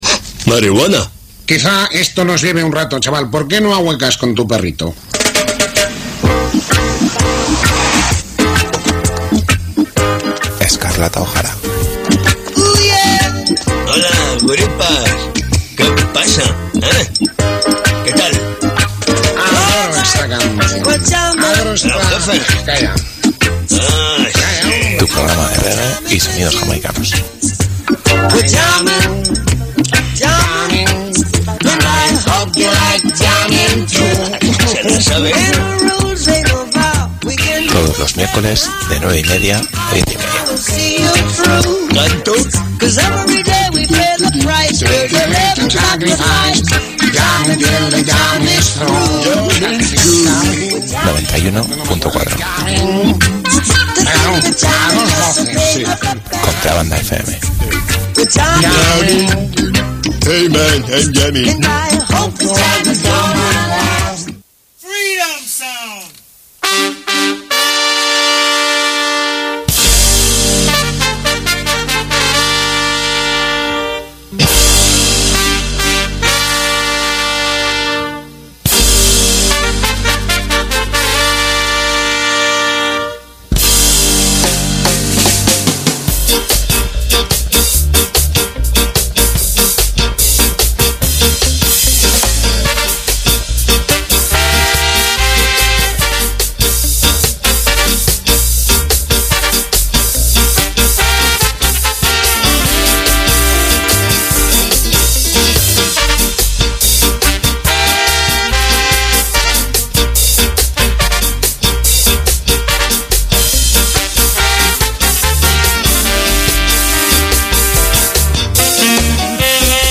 Pel darrer programa en directe de la temporada us hem preparat una selecció de música estiuenca: